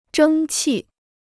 争气 (zhēng qì): ตั้งใจทำงาน; ตั้งใจพัฒนา.